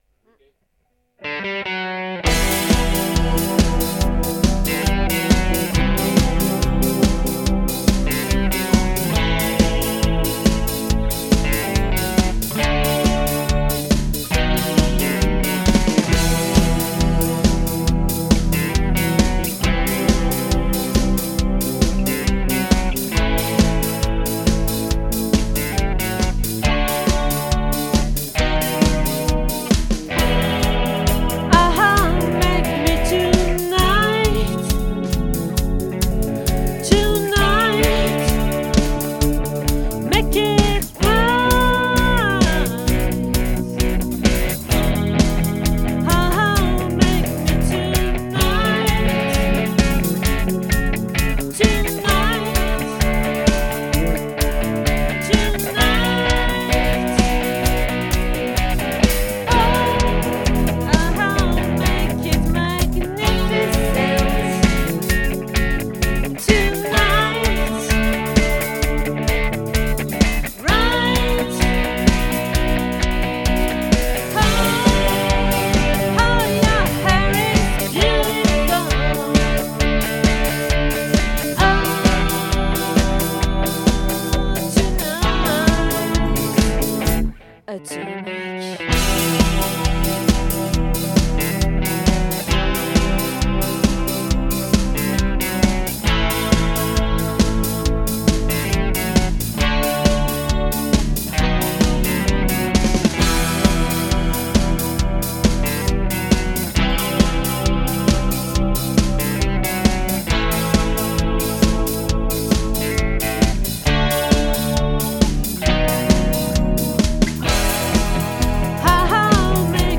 🏠 Accueil Repetitions Records_2022_02_02